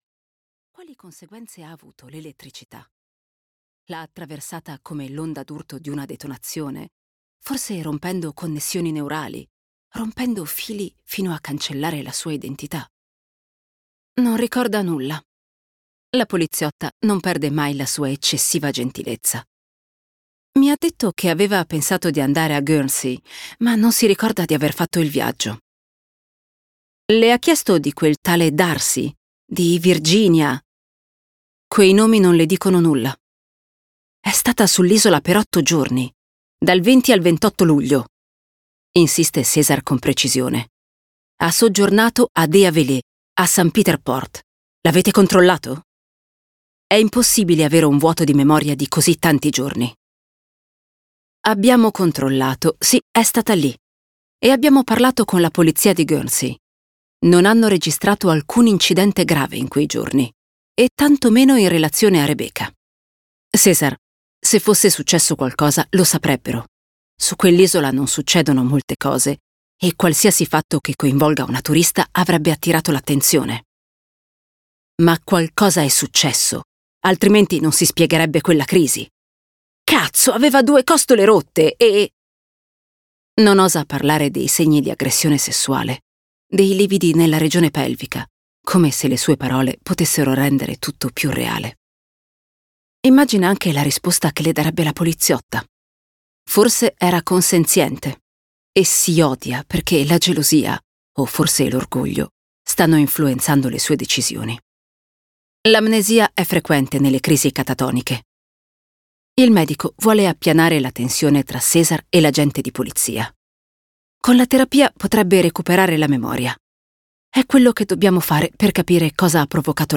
"La cacciatrice di eredità" di Agustín Martínez - Audiolibro digitale - AUDIOLIBRI LIQUIDI - Il Libraio